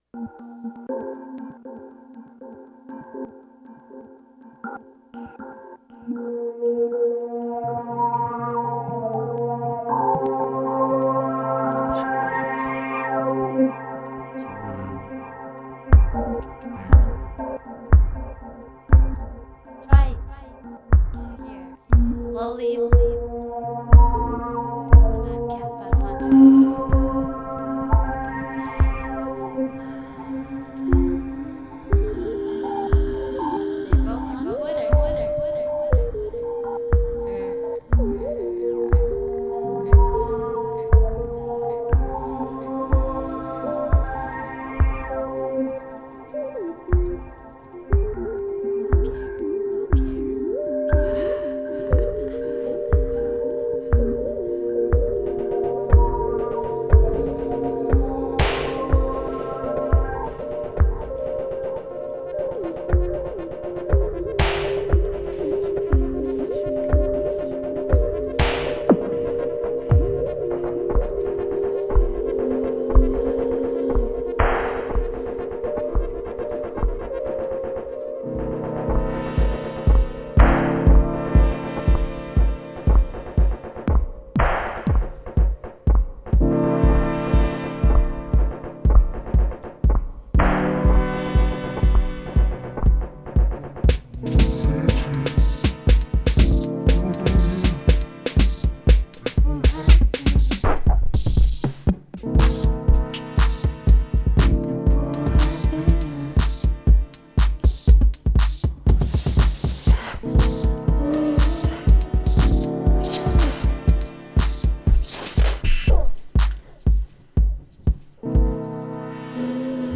came by the studio and jammed for a while
Ambient Avant-Garde Beats Electronic Synth